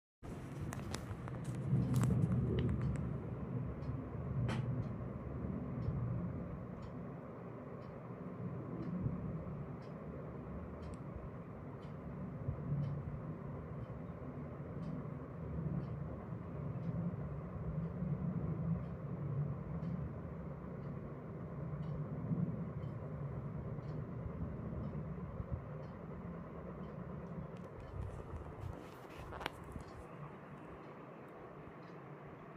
Ek ke baad ek jet udte ja rahen hain.
Foosh-Foosh,
Location: Very close to western command centre